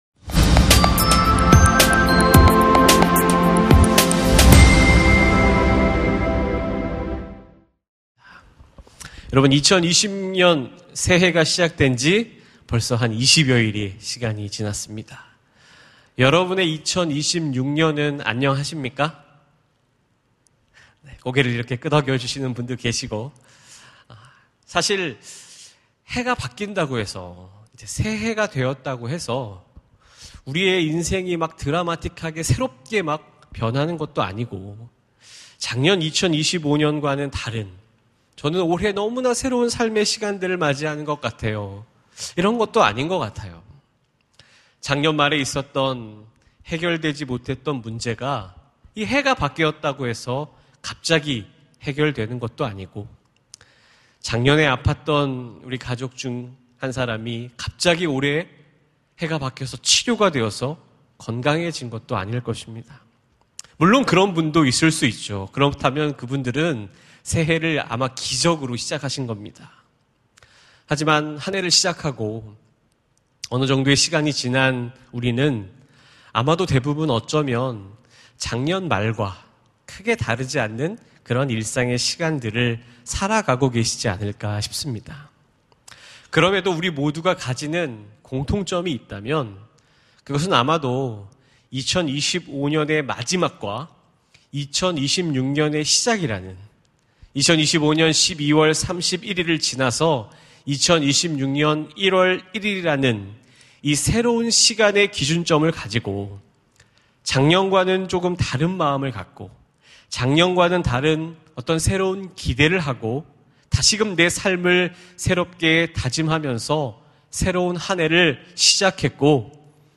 설교 : 수요향수예배